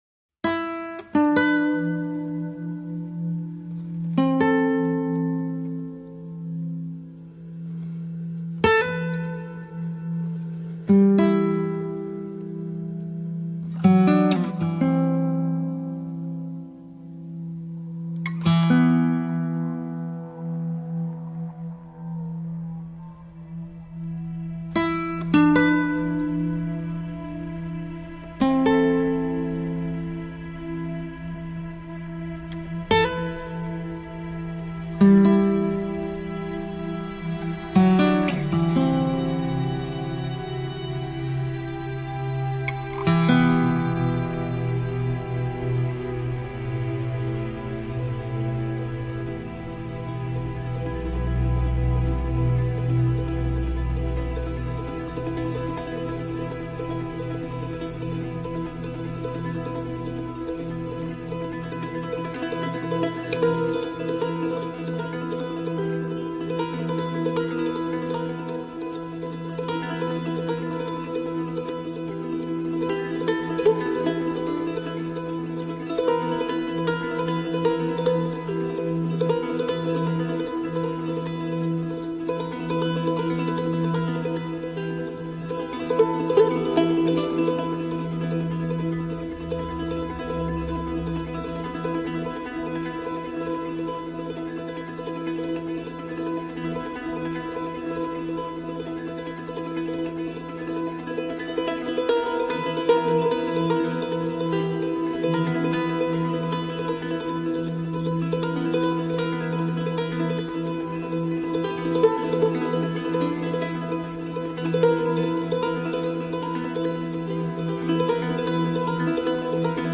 موزیک بی کلام معروف